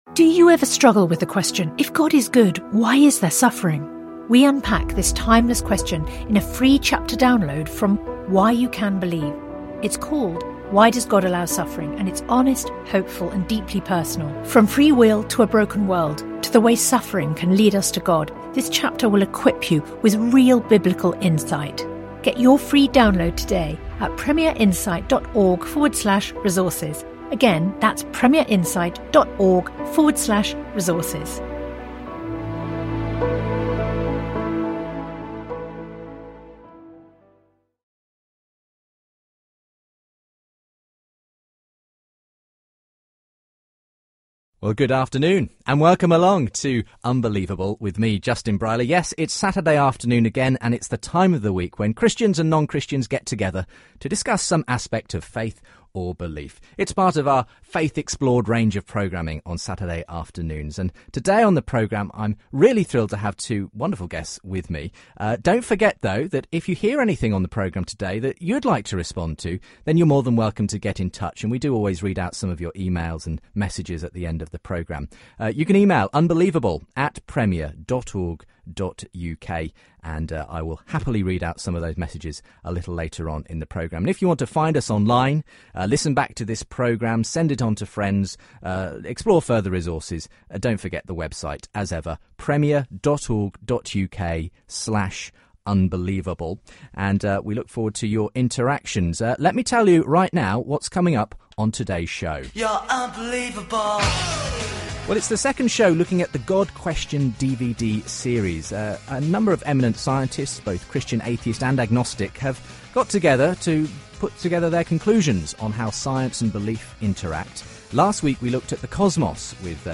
Christianity, Religion & Spirituality 4.6 • 2.3K Ratings 🗓 23 June 2014 ⏱ 81 minutes 🔗 Recording | iTunes | RSS 🧾 Download transcript Summary Christian philosopher Prof Keith Ward and atheist philosopher of science Michael Ruse debate some of the issues raised by the new DVD documentary series The God Question. It explores whether advances in science are undermining or supporting belief in God. Alongside audio clips from the film they discuss whether the human mind can be explained by material processes alone, religious experience and free will.